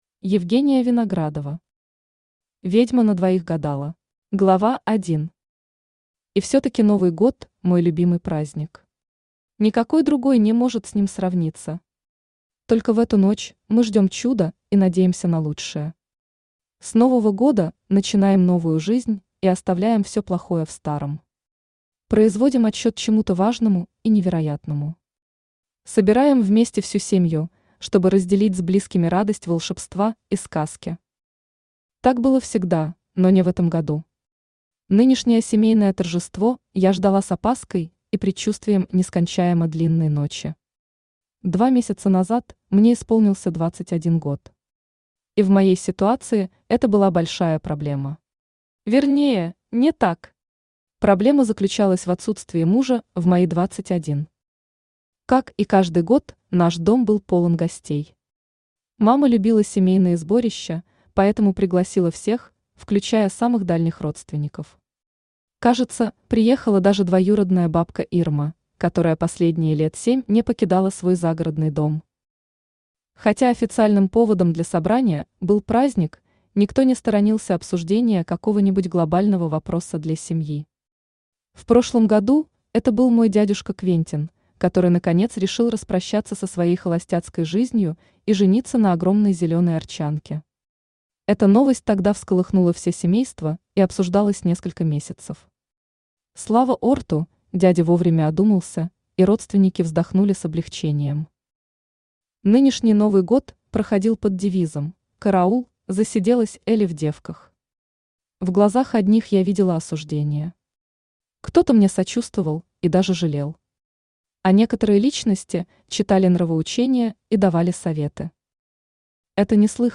Aудиокнига Ведьма на двоих гадала Автор Евгения Виноградова Читает аудиокнигу Авточтец ЛитРес.